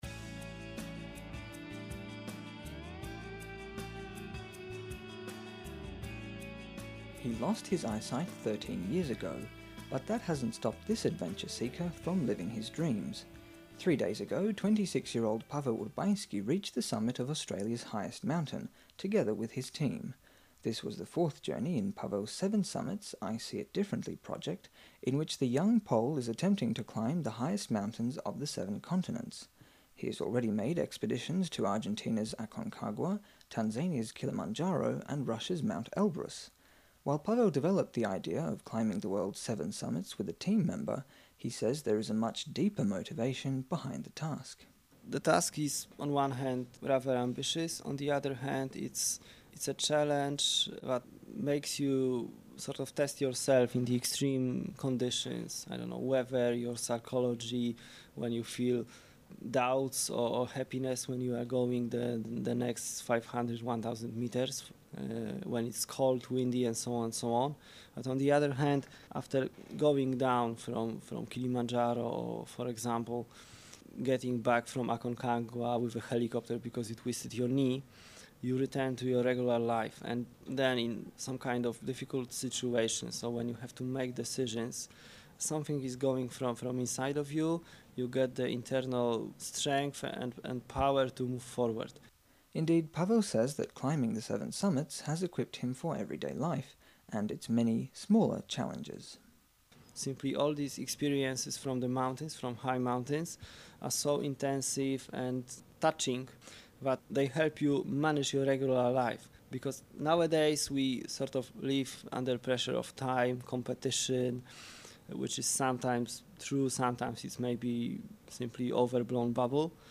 Climbing the Seven Summits: An Interview